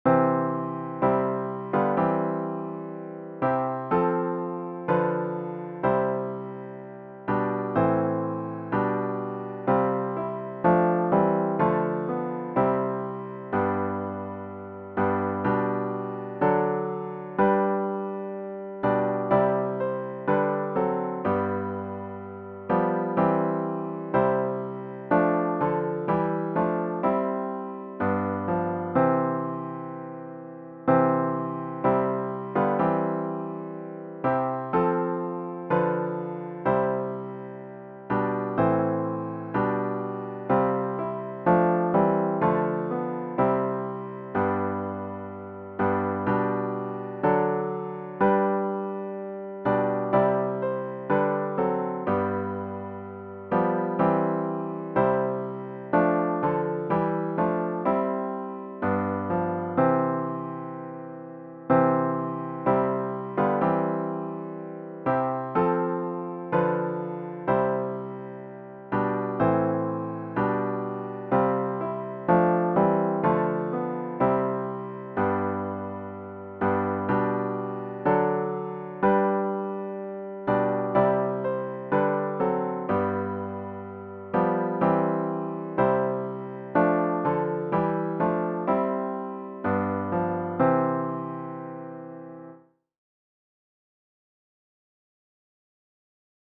OPENING HYMN   “Lift Up Your Heads, Ye Mighty Gates”   GtG 93
zz-093-Lift-Up-Your-Heads-Ye-Mighty-Gates-3vs-piano-only.mp3